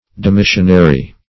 Search Result for " demissionary" : The Collaborative International Dictionary of English v.0.48: Demissionary \De*mis"sion*a*ry\, a. 1.